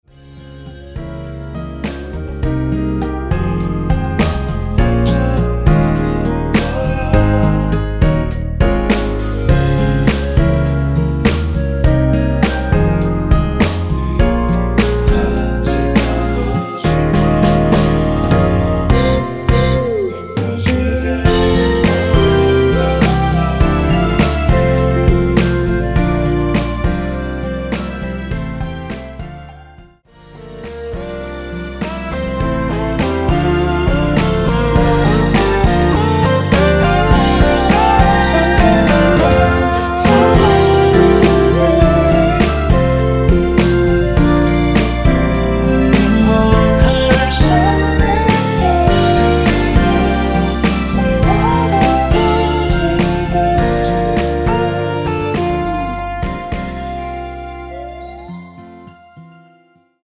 원키에서(-3)내린 멜로디와 코러스 포함된 MR입니다.(미리듣기 확인)
Ab
앞부분30초, 뒷부분30초씩 편집해서 올려 드리고 있습니다.